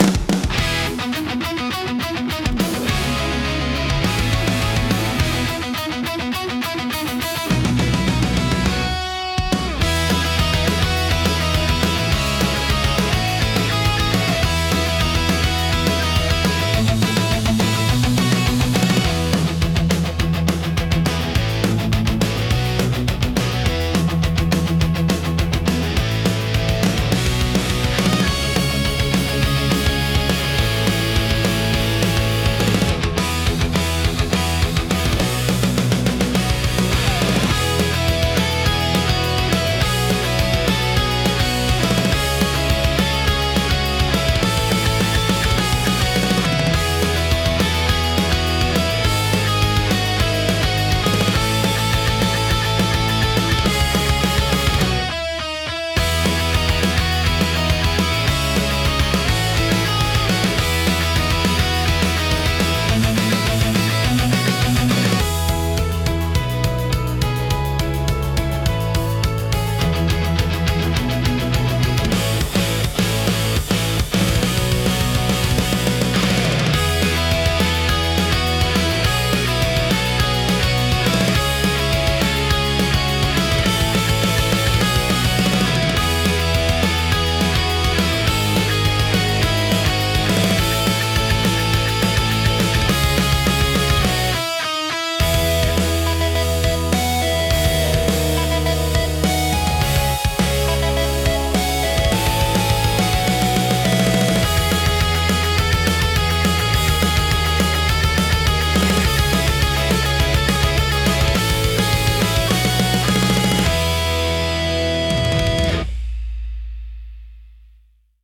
生々しさと疾走感を併せ持つ力強いジャンルです。